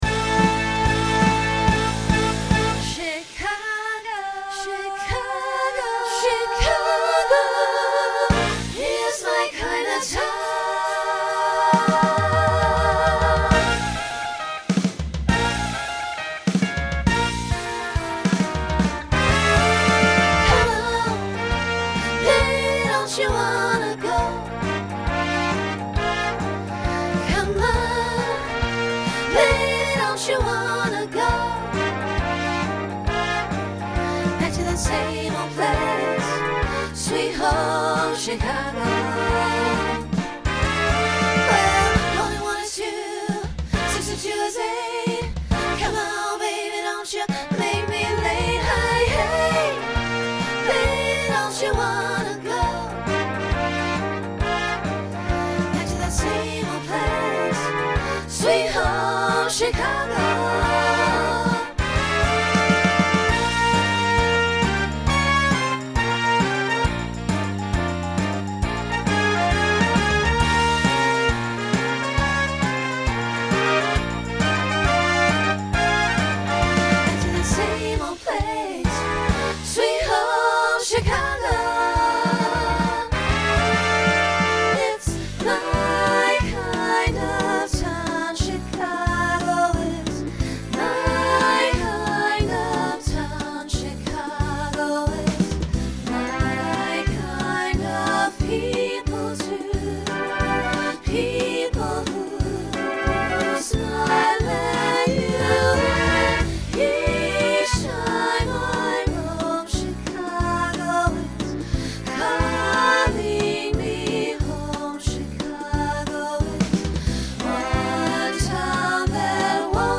Rock , Swing/Jazz
Voicing SSA